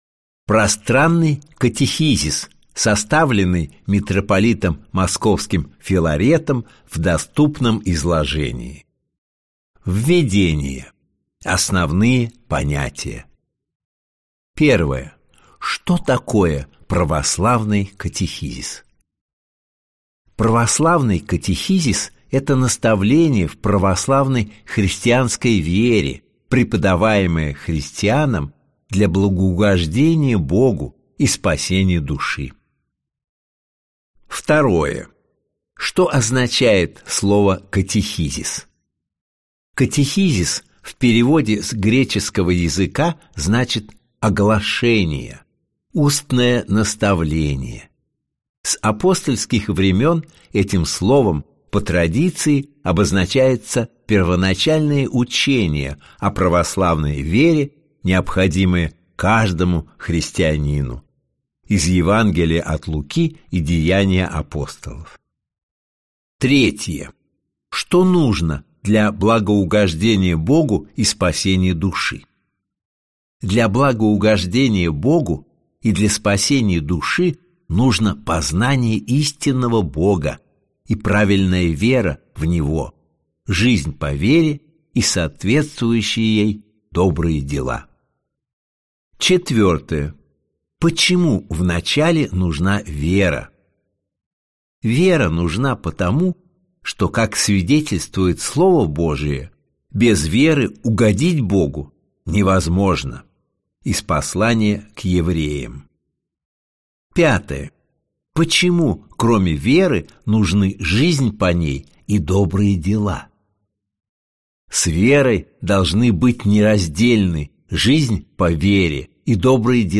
Аудиокнига Катехизис | Библиотека аудиокниг